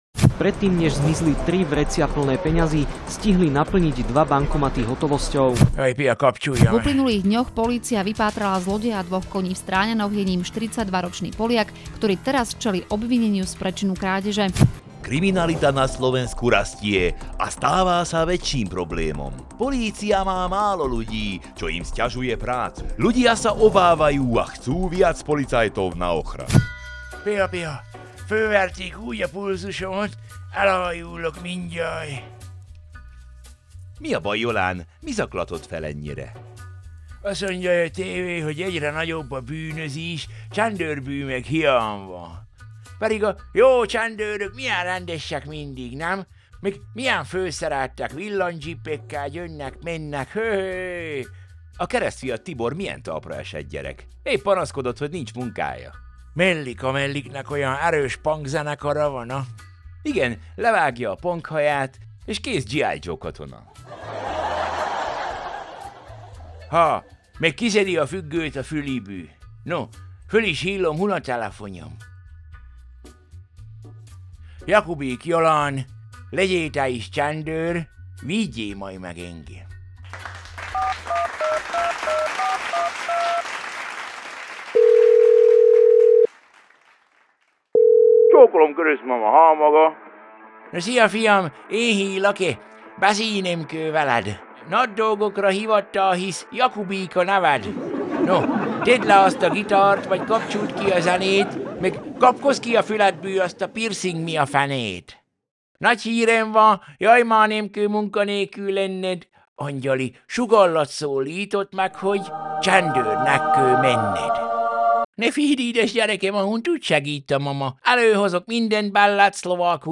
Nick Cave & The Bad Seeds - Red Right Hand (Instrumental)
George Thorogood & The Destroyers - Bad To The Bone